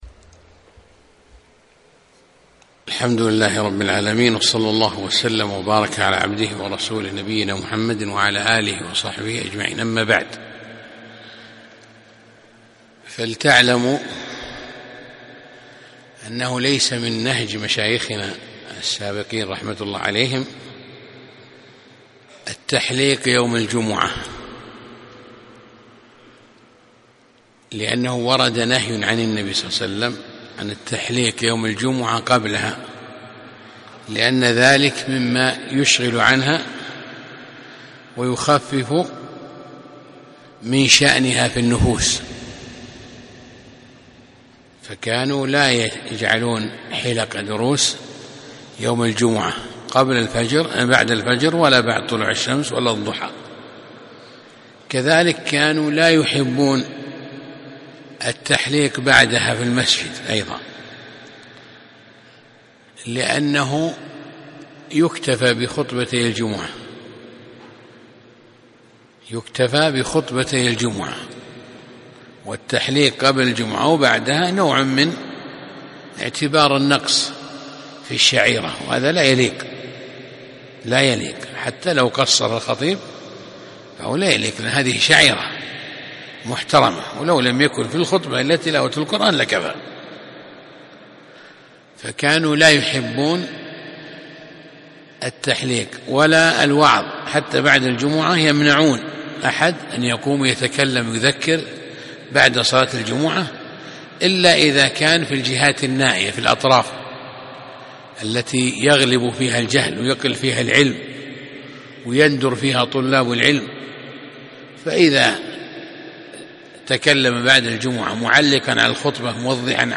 يوم الجمعة 17 جمادى الأولى 1437 في مسجد الشيخ
الدرس الثالث